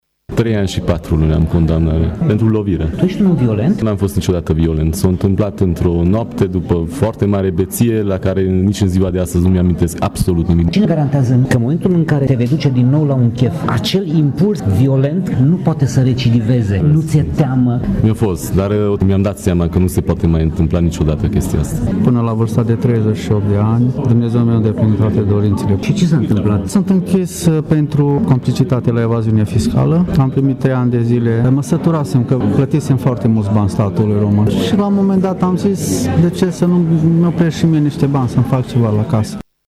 Ei au putut fi ”citiți” astăzi, la sediul ”American Corner” din Tîrgu Mureș, cu prilejul evenimentului ”Biblioteca vie”, aflat la a doua ediție.
Astfel, cei doi deținuți prezenți